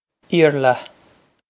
Eer-luh
This is an approximate phonetic pronunciation of the phrase.